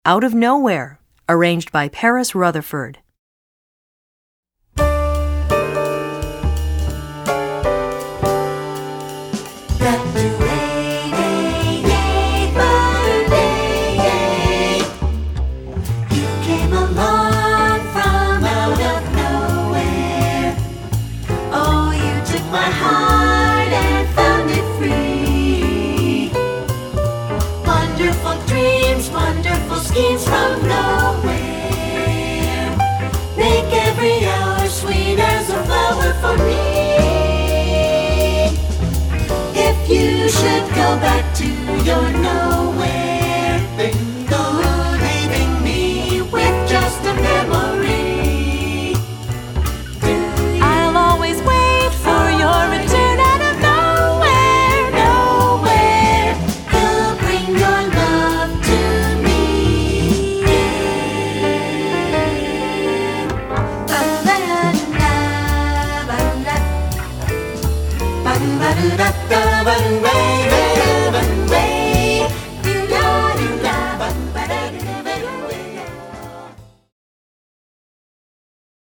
Voicing: Instrumental Parts